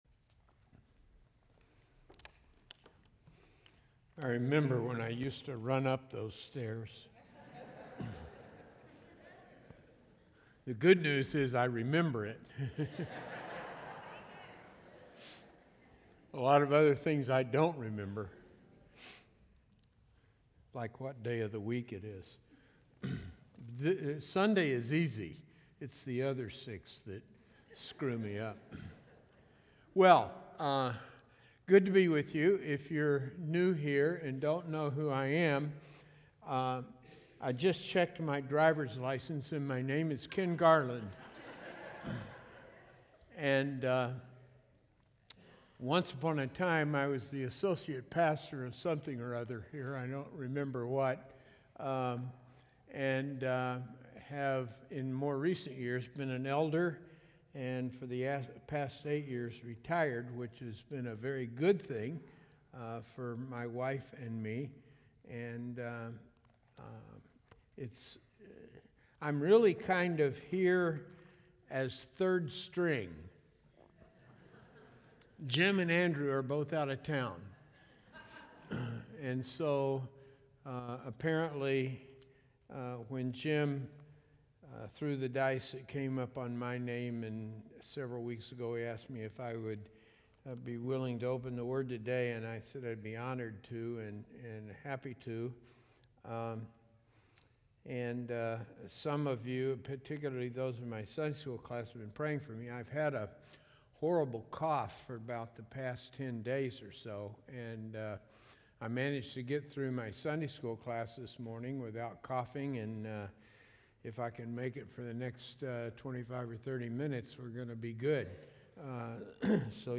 A message from the series "Galatians."